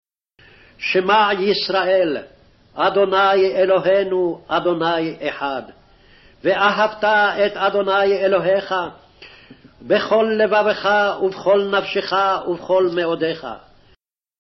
Original Speed |